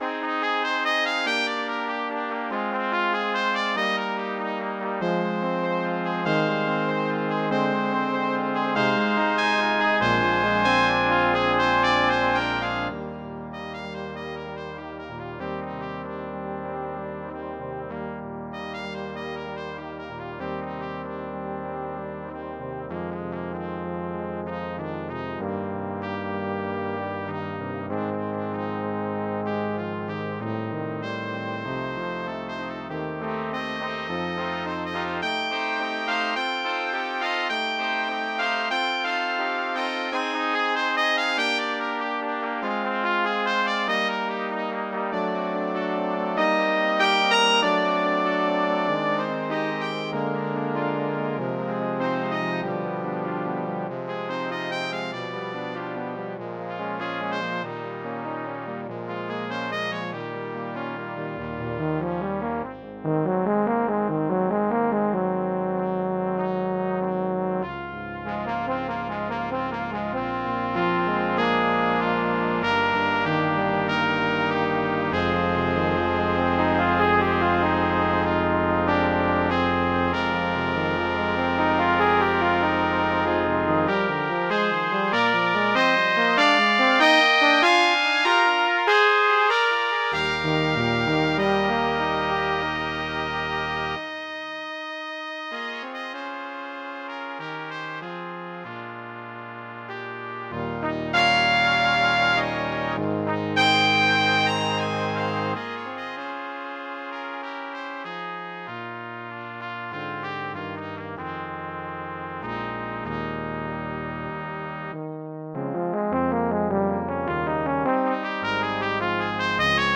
Music for Brass
transcribed for Brass Band.